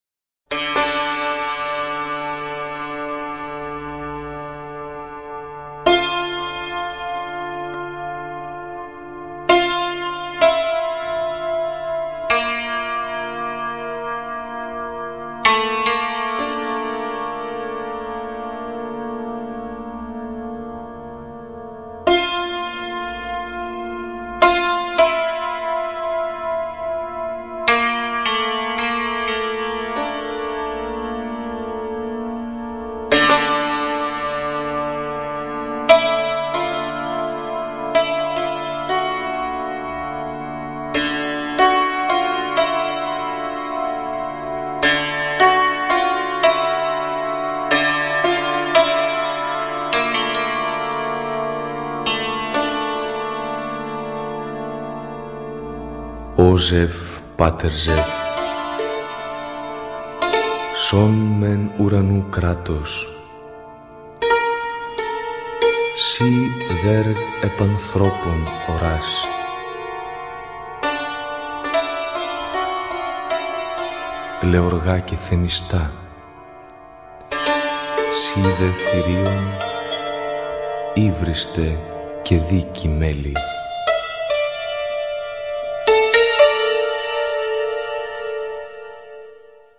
sengrieķu himna Zevam, azāns (aicinājums uz lūgšanu islāmā)
grieki_himnaZevam1.mp3